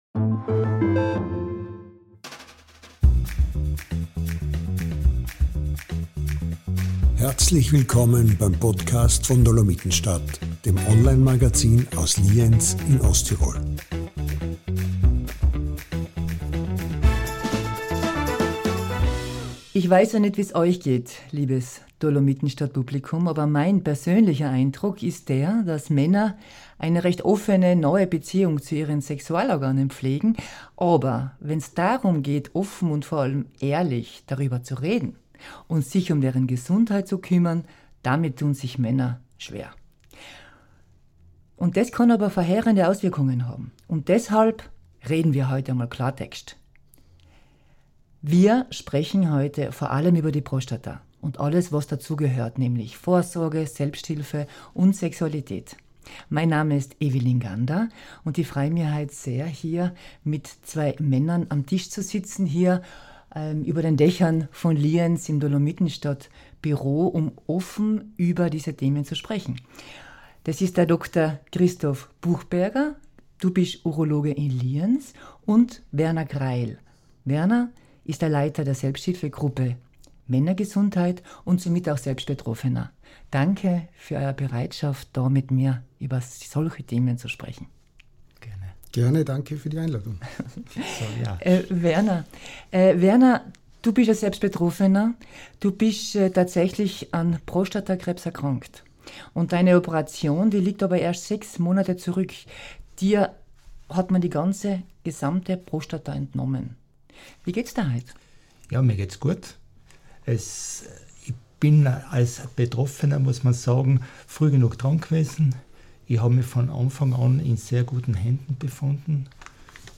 Ein Schwerpunkt dieses sehr offenen Podcastgesprächs liegt auf dem Umgang mit den Auswirkungen der Krankheit auf das Sexualleben.